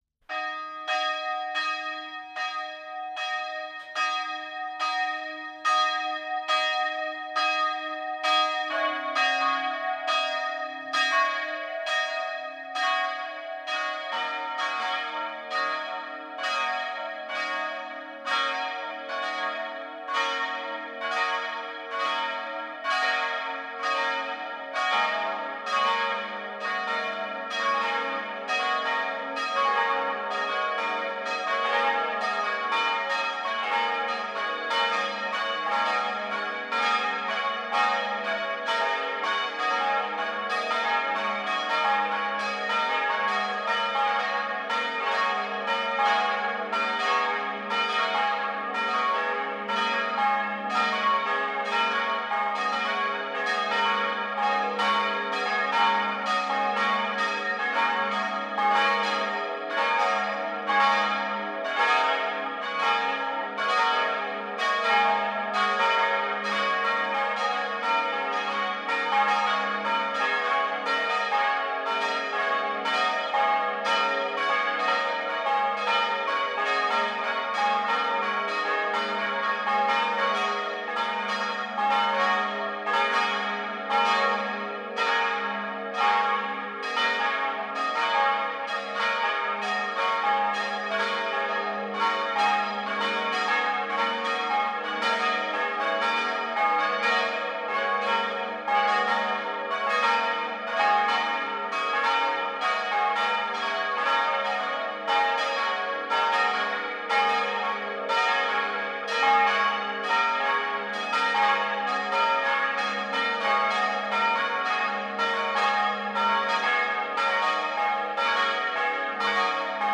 Glocken von St. Thomas anhören
glocken_st_thomas.mp3